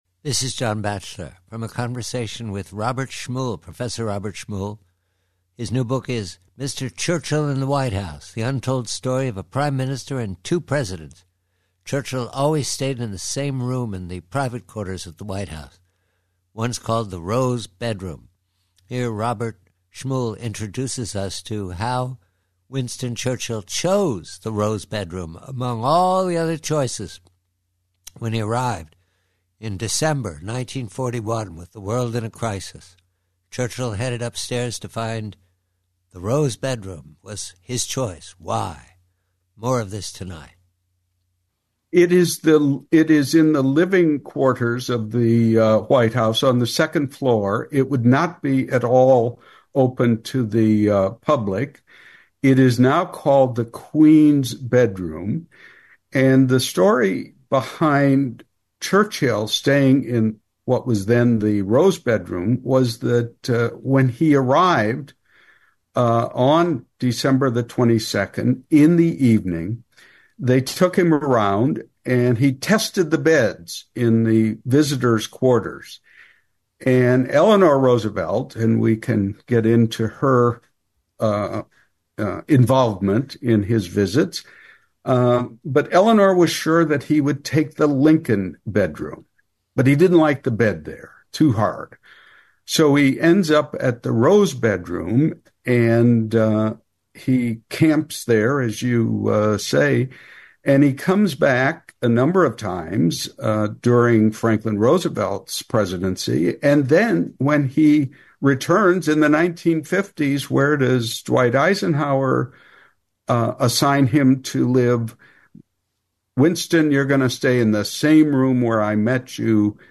Preview: Conversation